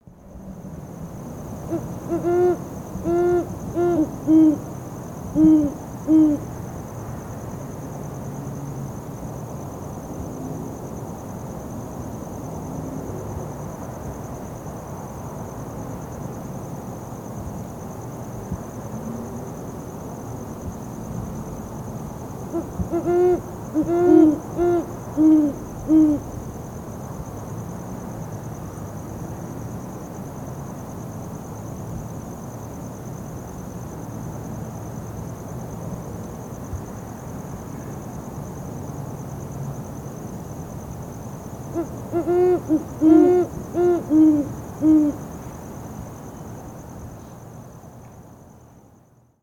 Recognizable by its booming five-note hoot: “hoo-hoo-hooooo hoo-hoo.”
Great Horned Owl:
recorded in Arizona, USA.